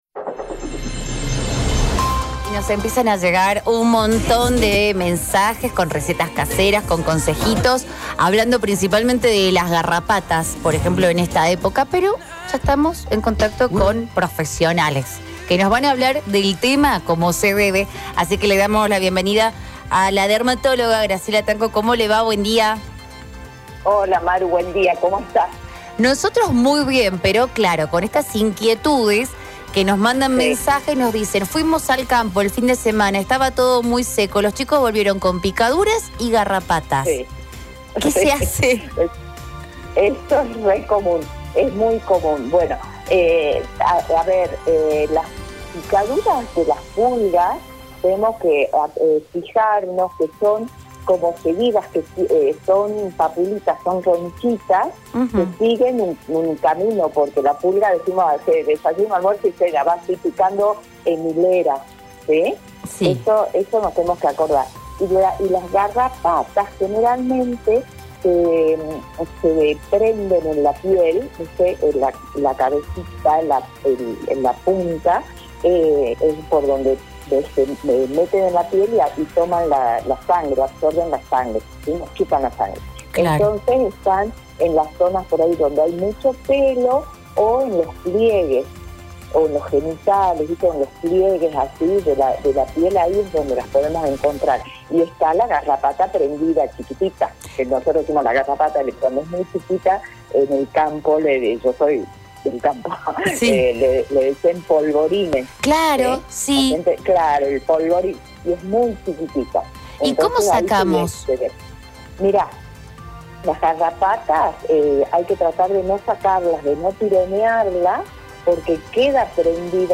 comentó en La Mañana de Radio City sobre las variantes en las picaduras de las pulgas y garrapatas.